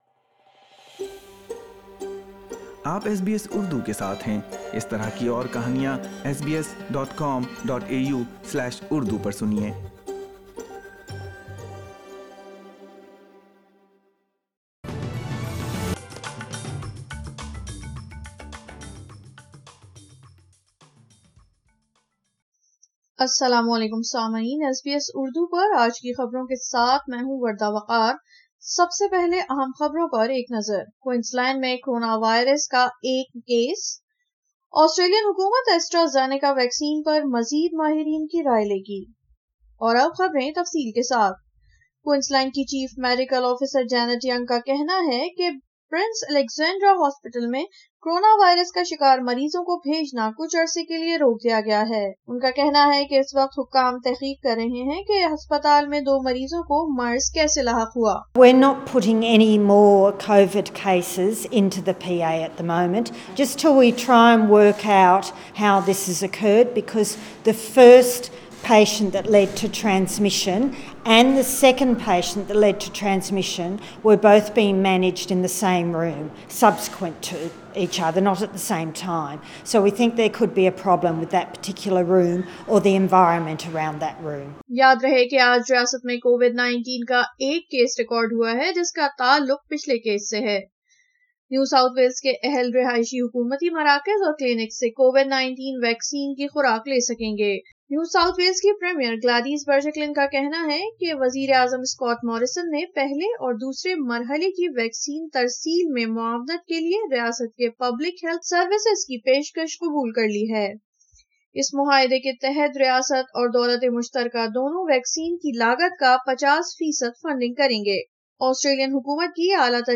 اردو خبریں 03 اپریل 2021